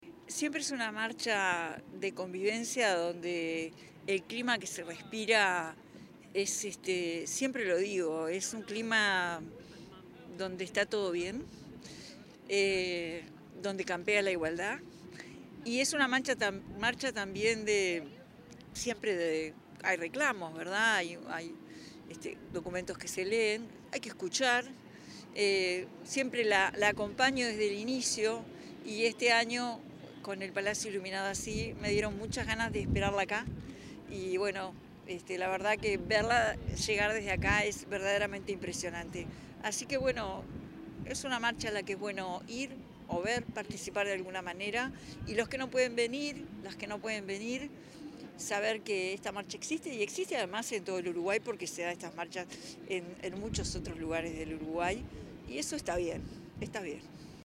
En ocasión de la Marcha de la Diversidad 2025, la presidenta de la República en ejercicio, Carolina Cosse, dialogó con la prensa.